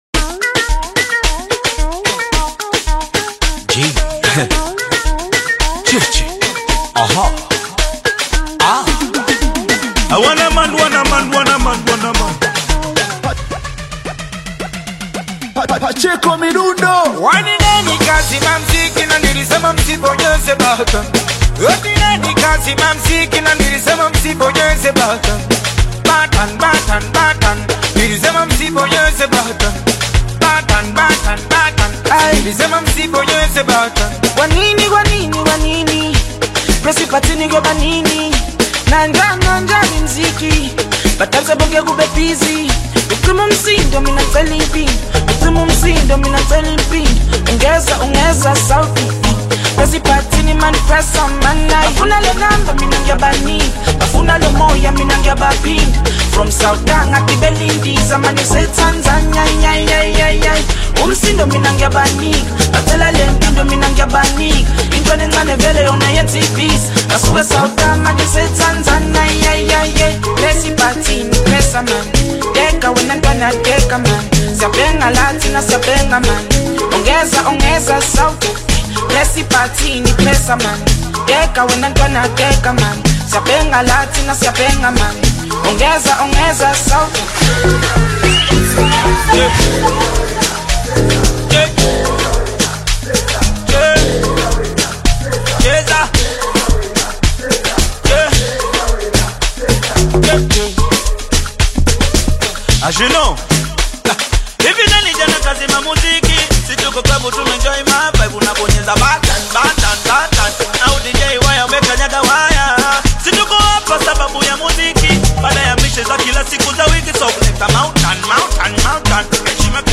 high-energy Tanzanian Singeli/Bongo Flava collaboration
energetic delivery
dancefloor-ready anthem